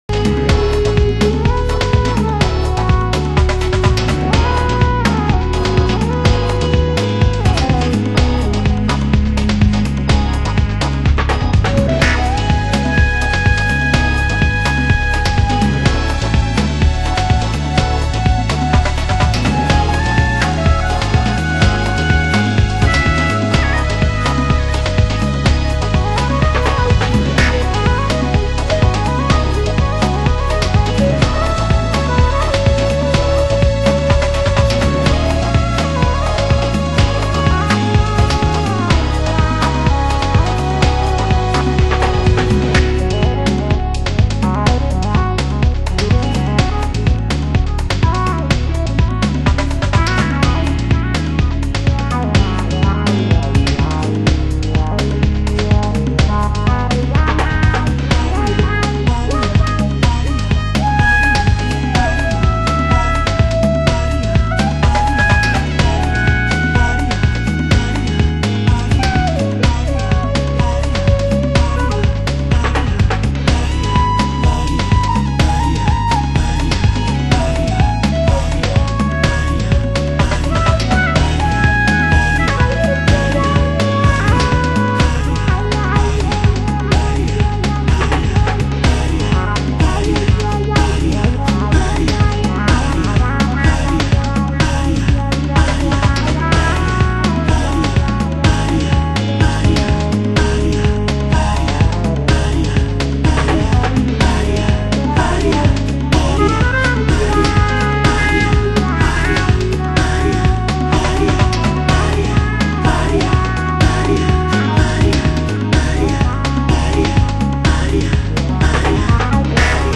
HOUSE MUSIC
盤質：良好/盤面に小さな白い付着部がありますが、サウンドに大きな影響はありません。